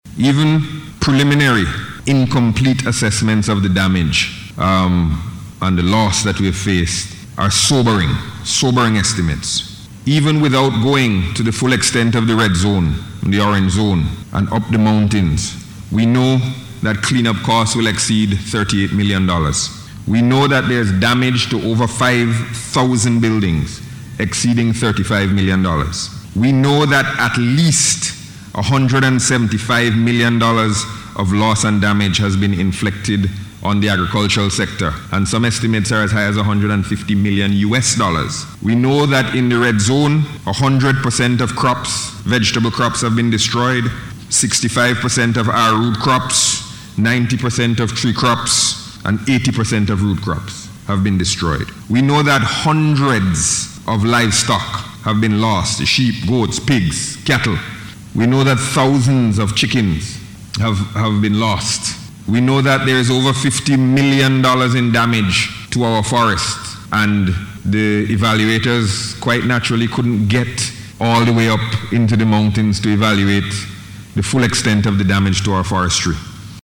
Presenting the Bill in Parliament, Minister of Finance, Camillo Gonsalves described the explosive volcanic eruption as devastating.